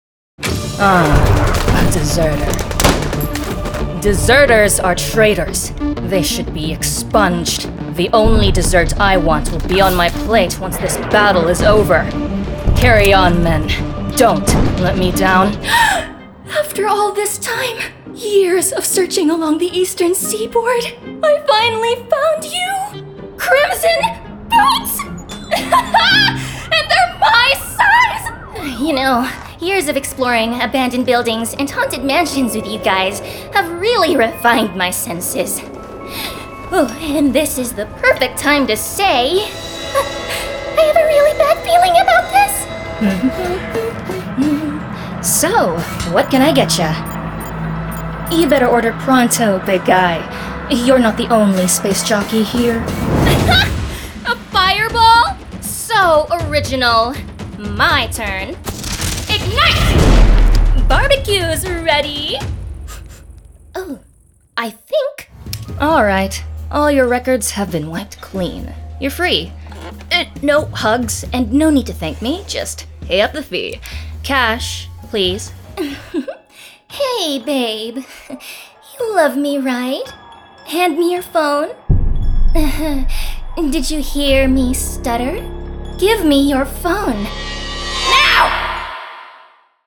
Character Demo
British RP, General American, American Southern, Australian, Russian, German, Filipino
My vocal quality is first and foremost youthful, earnest, grounded and natural but I do have so many more capabilities under my belt.